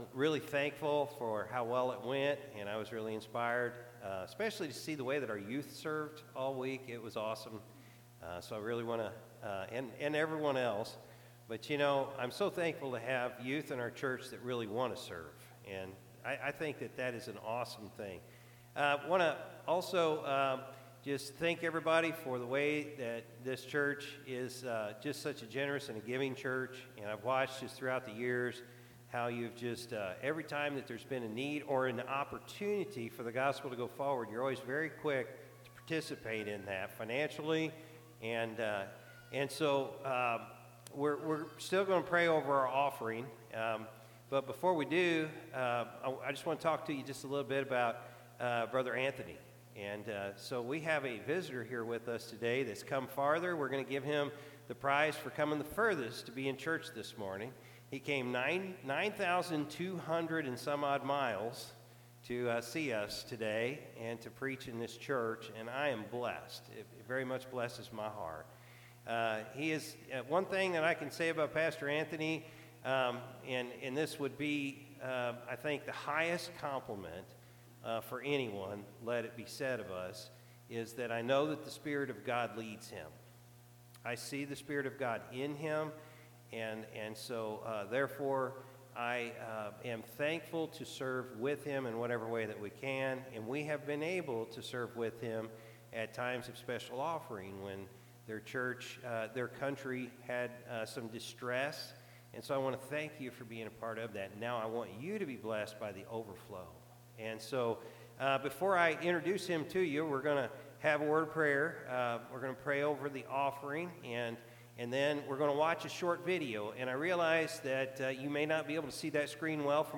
July-2-2023-Morning-Service.mp3